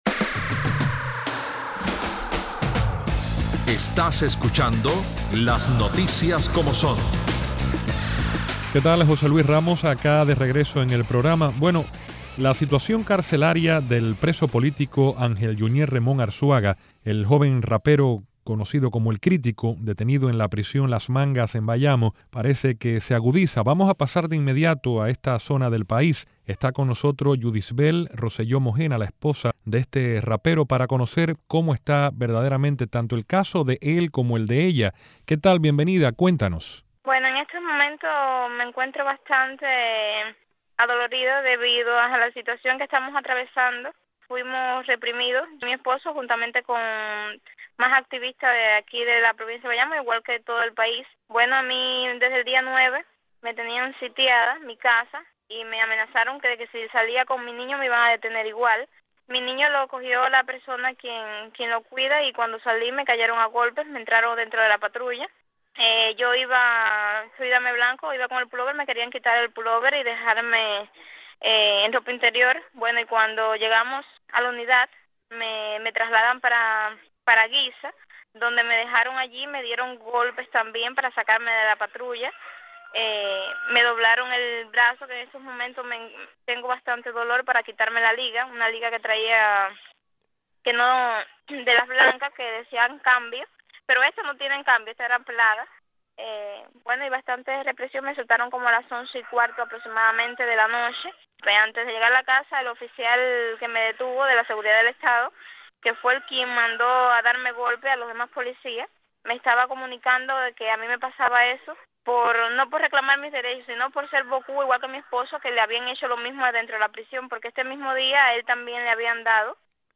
nuestros analistas políticos